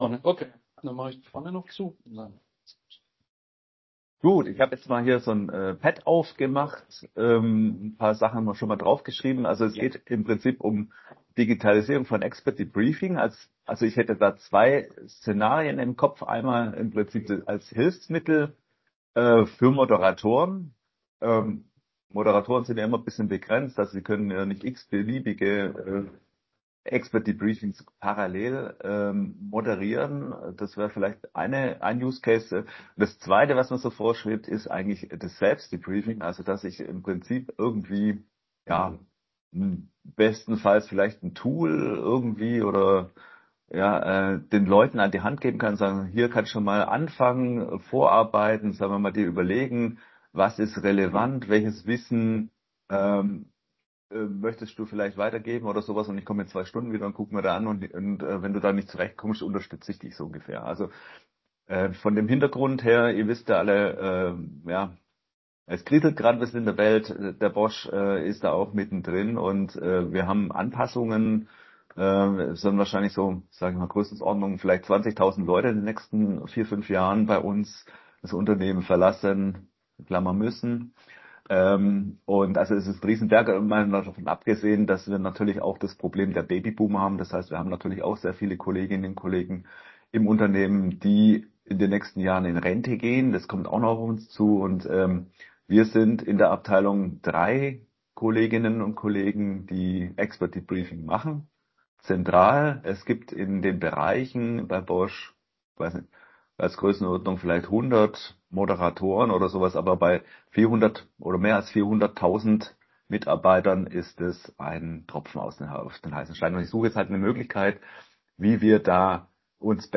Die Teilnehmer tauschten sich über Tools, Methoden und Herausforderungen aus, insbesondere vor dem Hintergrund demografischer Veränderungen und größerer Personalabgänge. Zentrale Themen waren die Balance zwischen digitalen und Präsenz-Formaten, selbstgesteuertes Debriefing sowie die Frage nach spezialisierten Tools versus vorhandene Standard-Software.